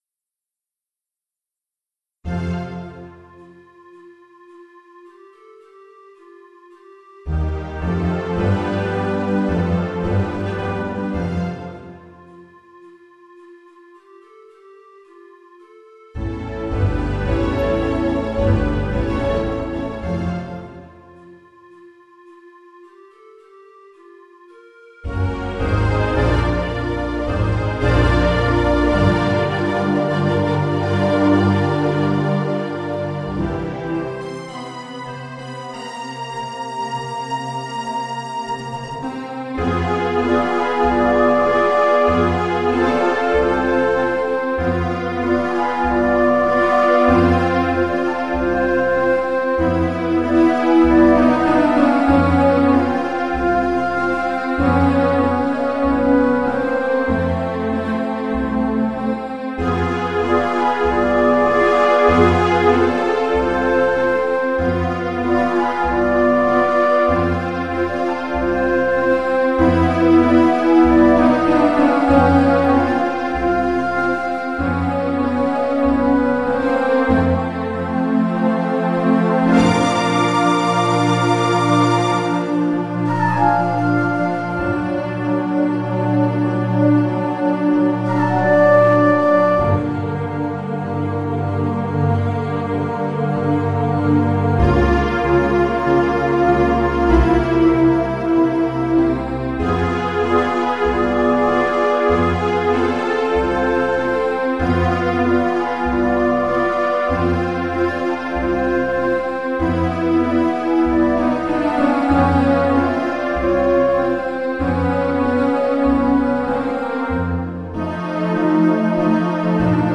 featured voice